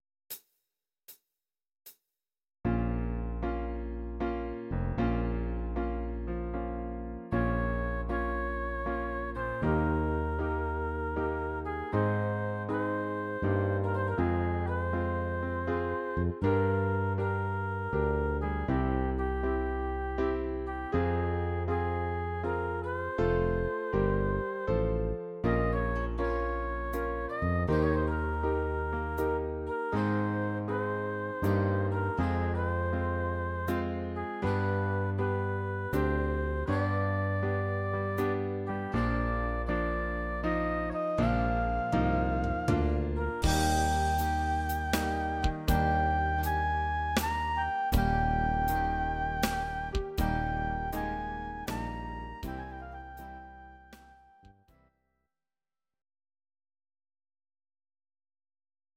Please note: no vocals and no karaoke included.
Your-Mix: Country (822)